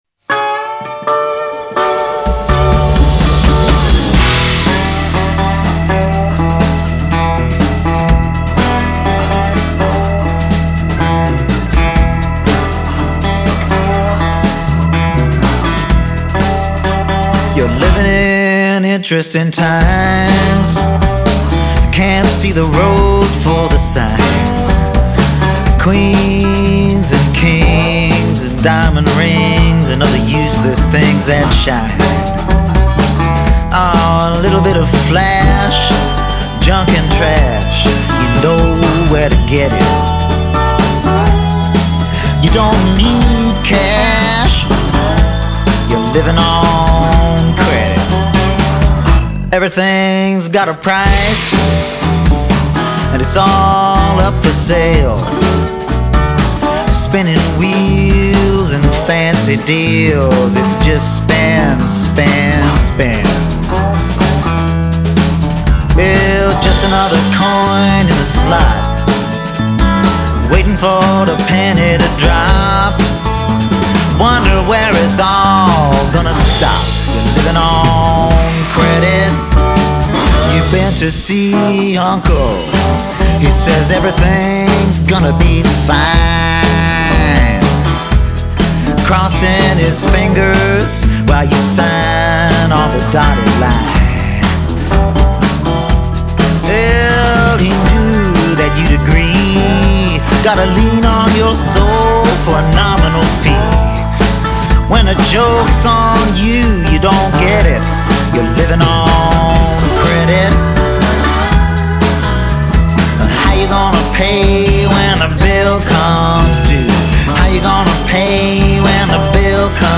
hard hitting harp playing